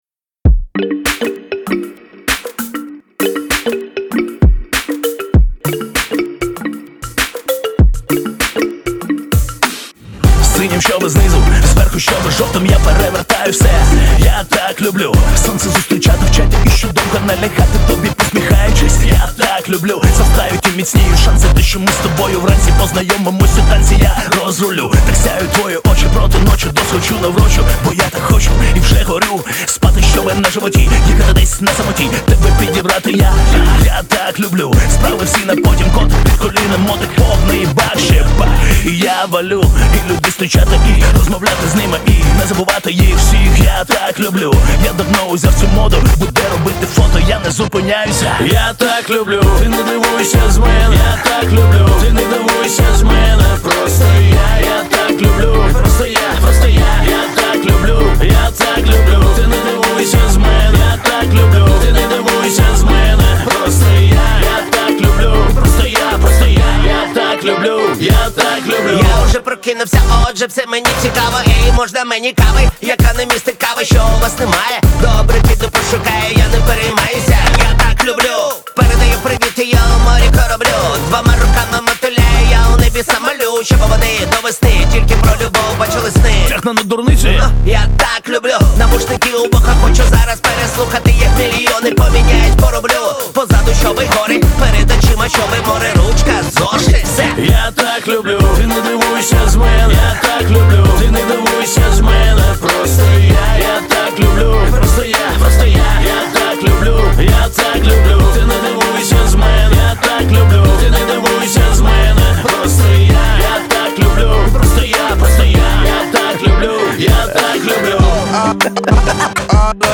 • Жанр: Hip-Hop